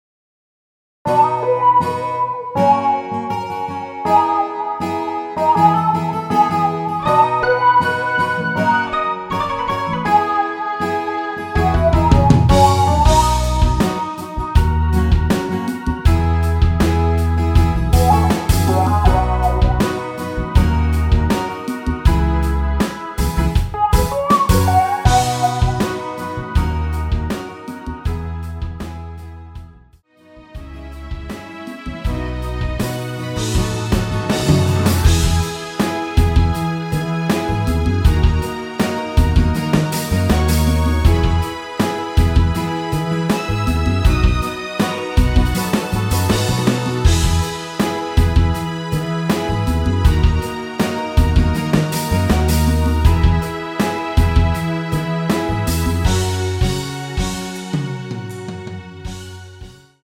MR 입니다.
앞부분30초, 뒷부분30초씩 편집해서 올려 드리고 있습니다.
중간에 음이 끈어지고 다시 나오는 이유는
곡명 옆 (-1)은 반음 내림, (+1)은 반음 올림 입니다.